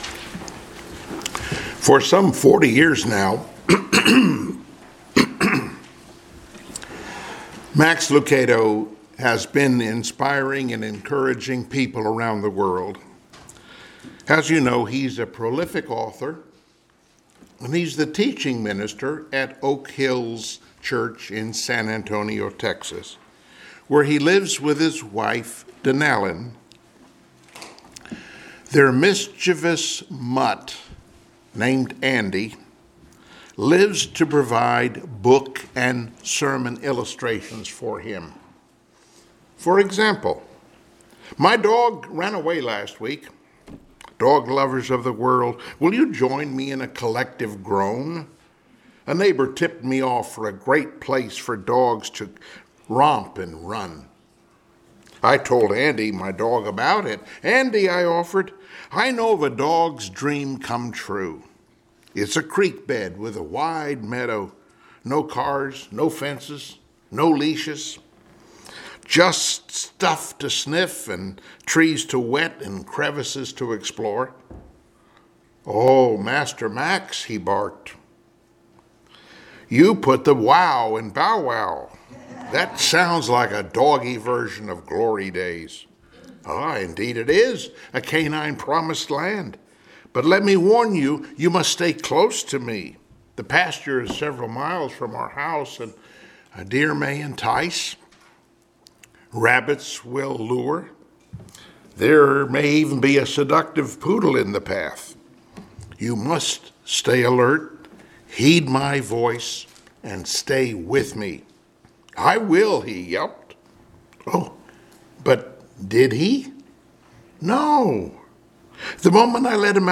Passage: Joshua 1:7-18 Service Type: Sunday Morning Worship